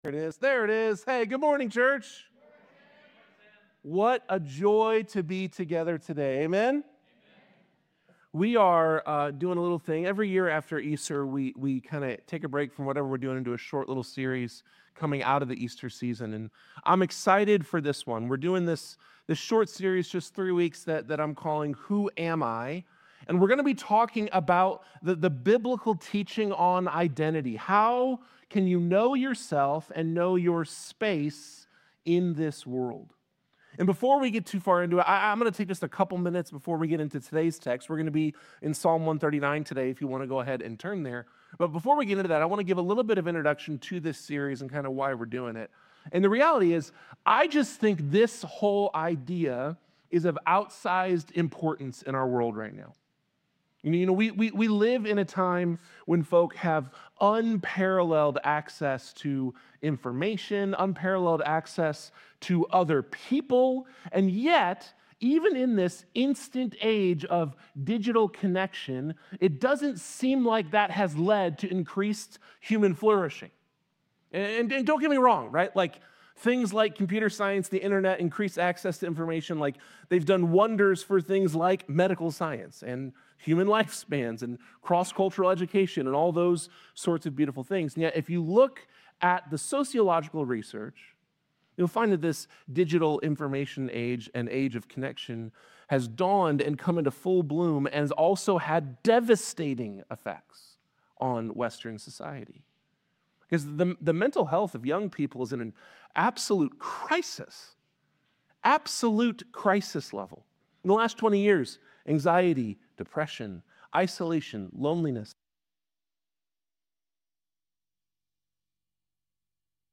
Whether you struggle with body image, anxiety, or simply feeling at home in your own skin, this sermon explores how embracing your physical identity leads to a life of significance and peace.